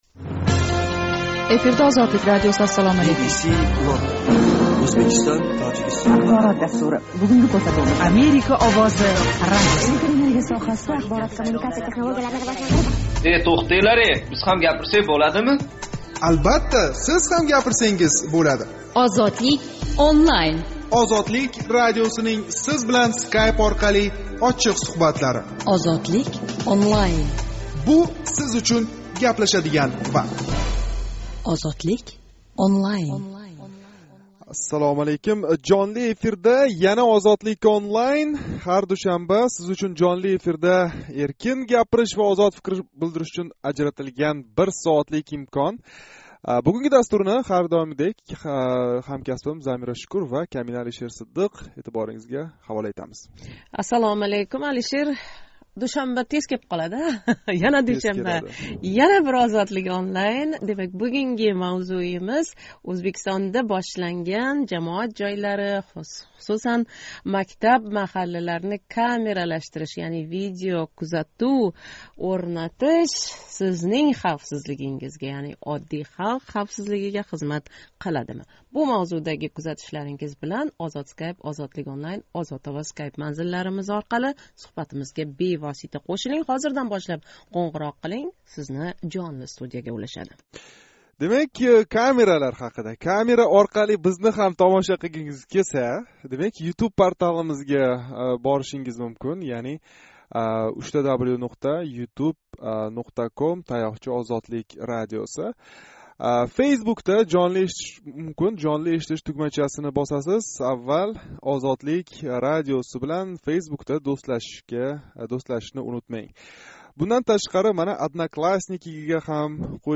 Мамлакатни бундай камералаштириш Сиз ва оилангизнинг бехавотирроқ яшашига хизмат қиладими? Душанба кунги жонли суҳбатимиз шу ҳақда бўлади.